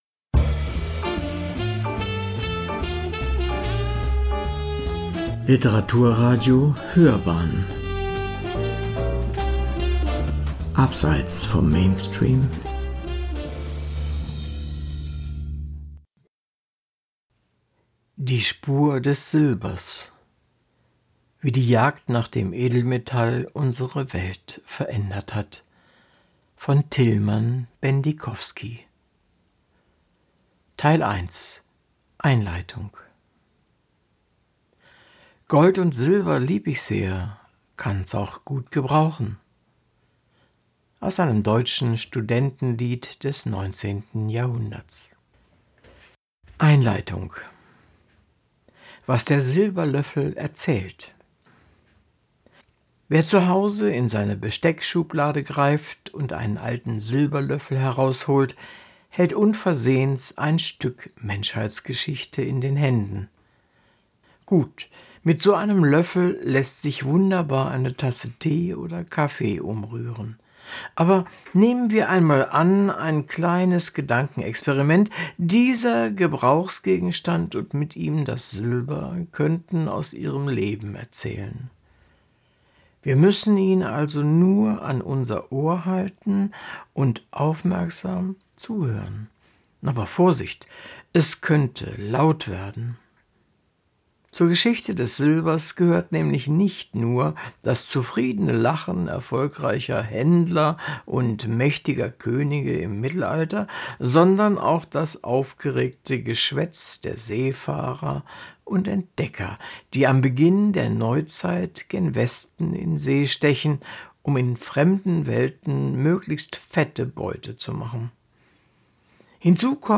Wir lesen 12 kleine Auszüge, die Einblick in historische Hintergründe, erzählerische Feinheiten und die besondere Atmosphäre des Textes geben.